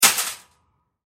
ShoppingCartAdd.mp3